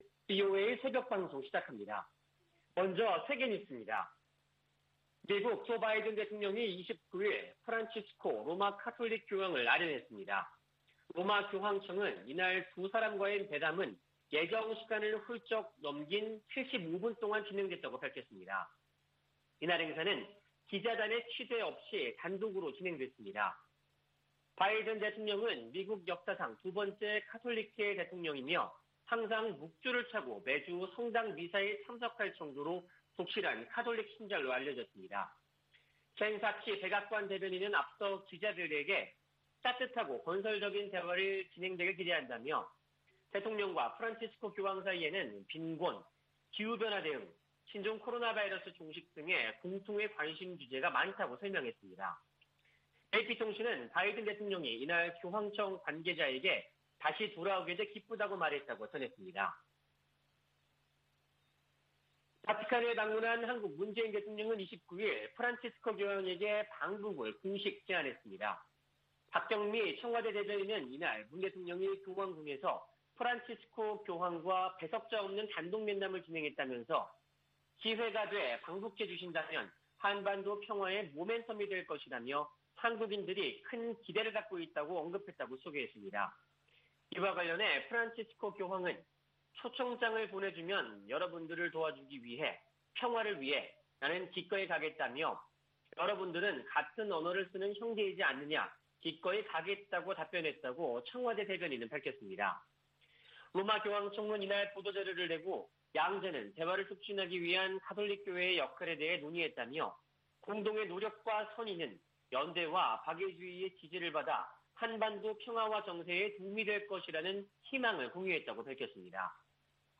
VOA 한국어 '출발 뉴스 쇼', 2021년 10월 30일 방송입니다. 북한 신의주와 중국 단둥간 철도 운행이 다음달 재개될 가능성이 있다고 한국 국가정보원이 밝혔습니다. 유럽연합이 17년 연속 유엔총회 제3위원회에 북한 인권 상황을 규탄하는 결의안을 제출했습니다. 유엔총회 제1위원회에서 북한 핵과 탄도미사일 관련 내용 포함 결의안 3건이 채택됐습니다.